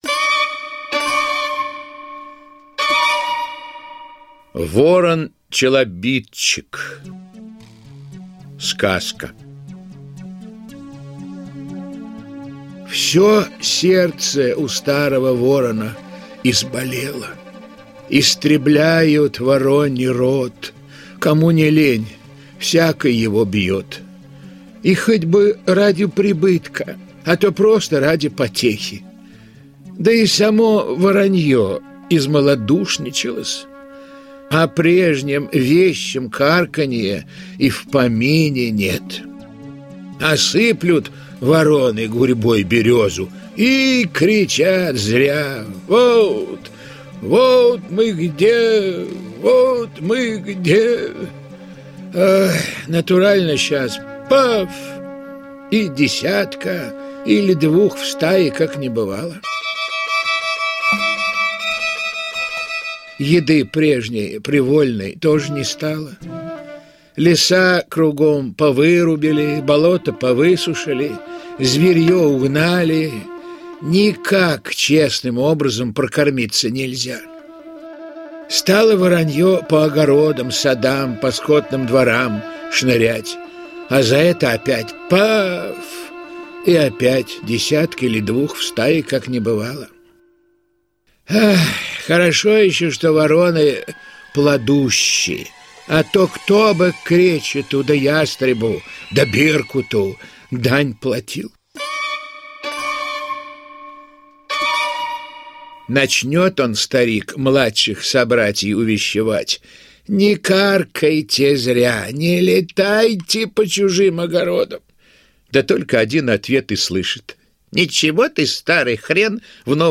Ворон-челобитчик - аудиосказка Михаила Салтыкова-Щедрина - слушать онлайн